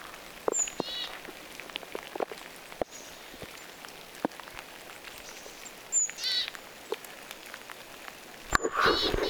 Sateessa luontopolulla kävelemässä.
tuollainen hömötiainen